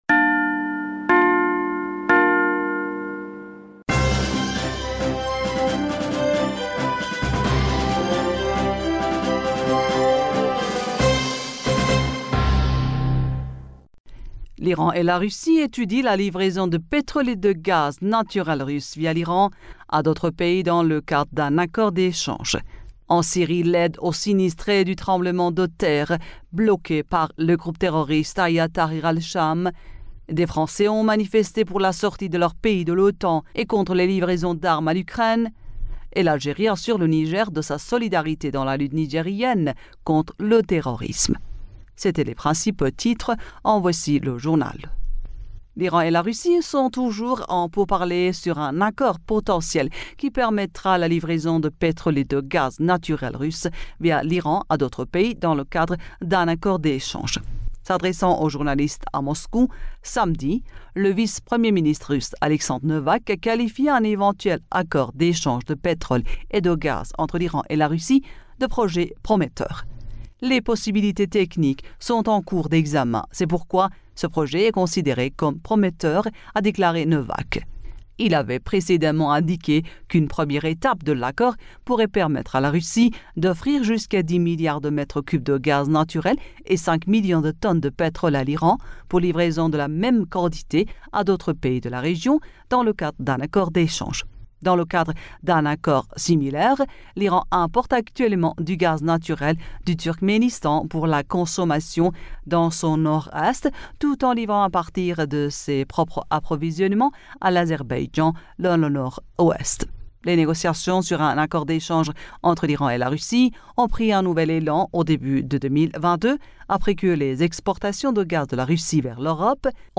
Bulletin d'information du 13 Février